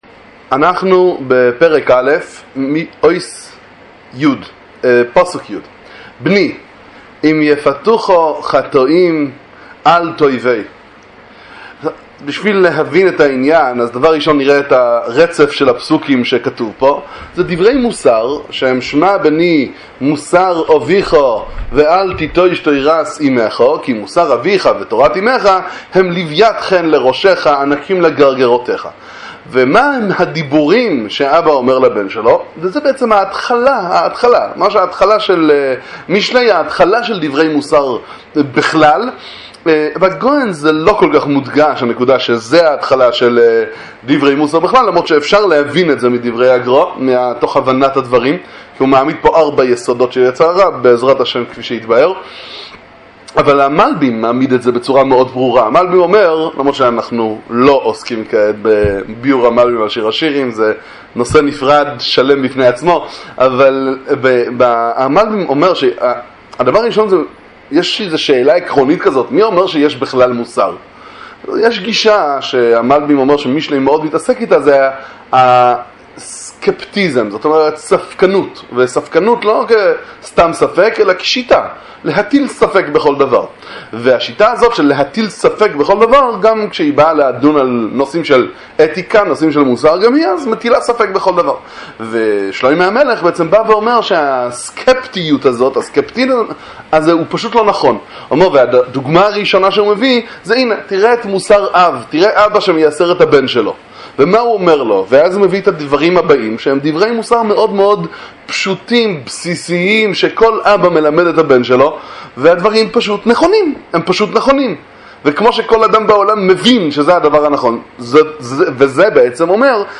שיעורים בספר משלי עם ביאור הגר"א, דברי תורה ומוסר מהגאון מוילנא